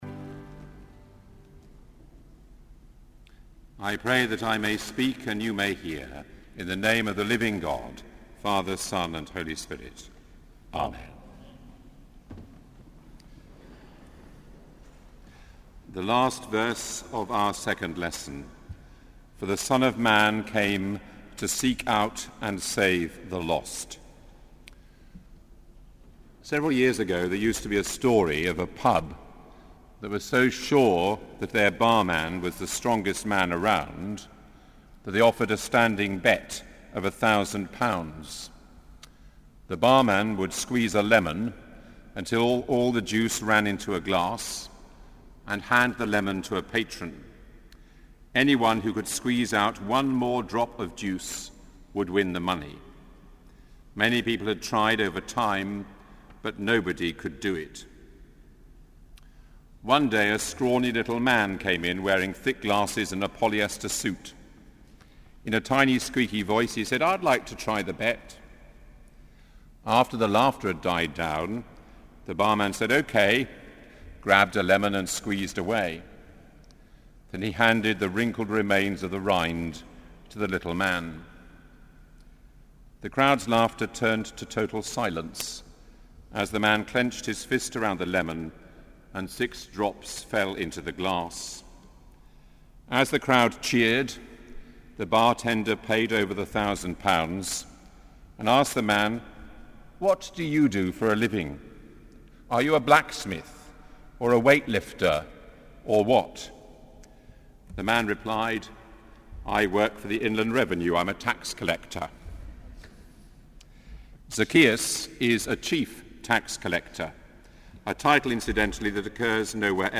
Sermon: Evensong - 6 July 2014